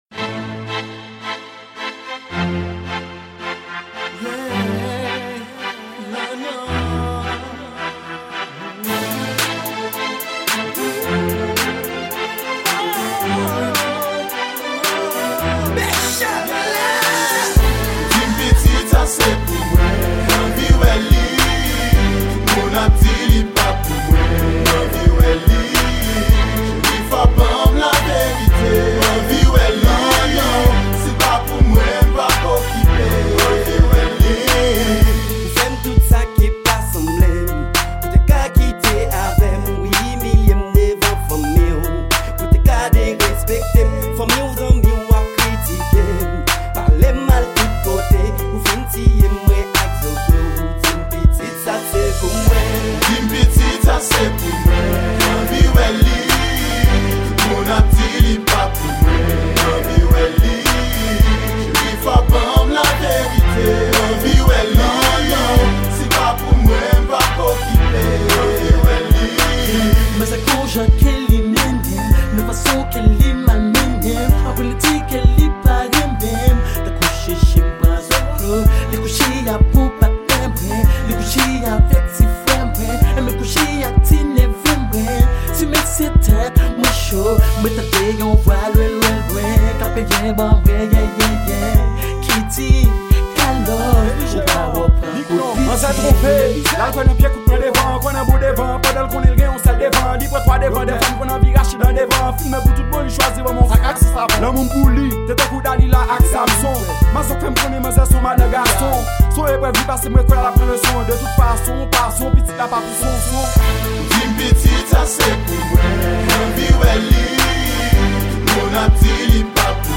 Genre: Hip-Hop.